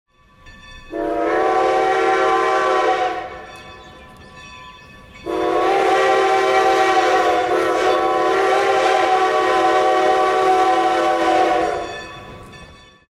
Звуки гудков паровоза
Гудки паровозов звучание